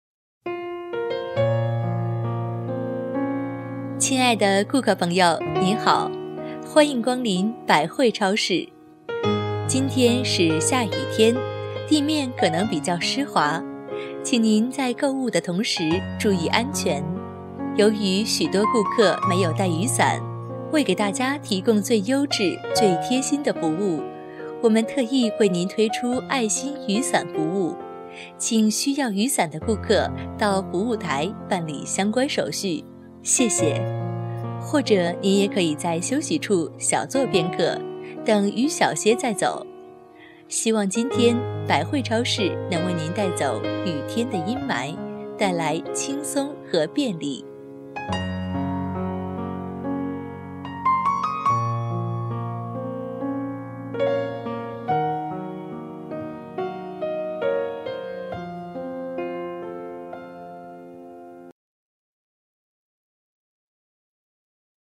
女声配音
提示音女国73B